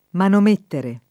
manomettere [ manom % ttere ]